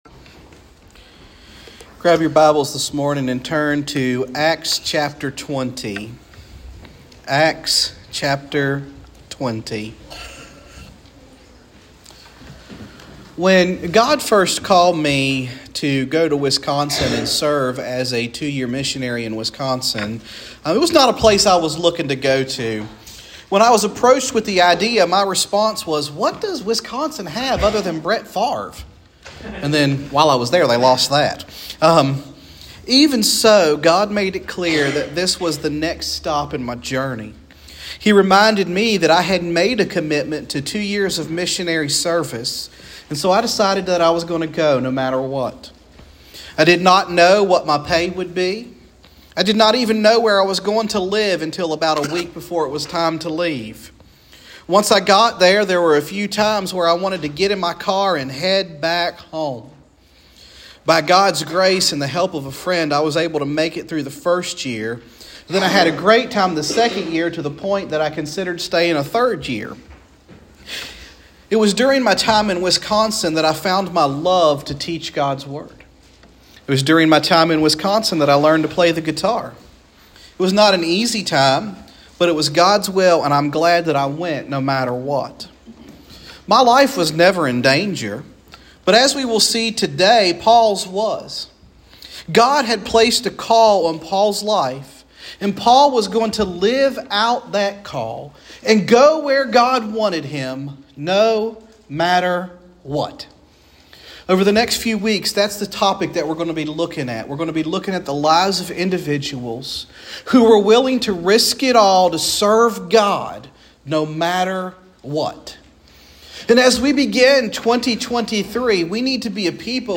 Sermons | Hopewell First Baptist Church